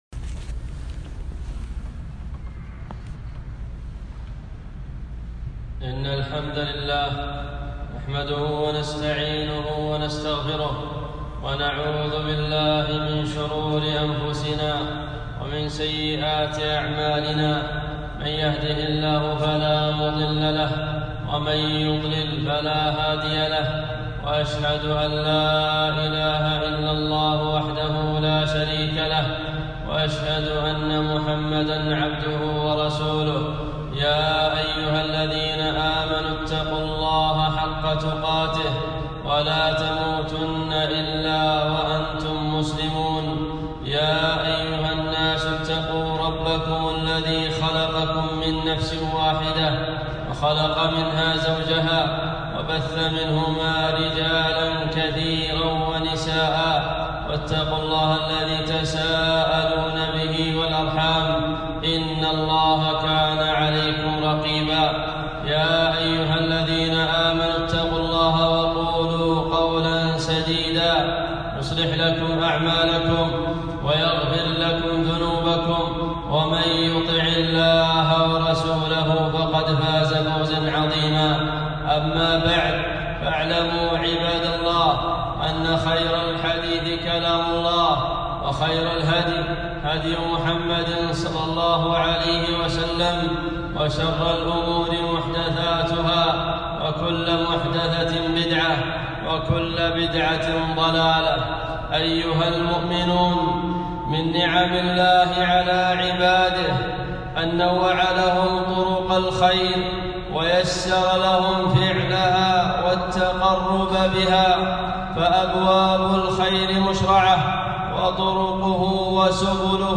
خطبة - المسارعة إلى الخيرات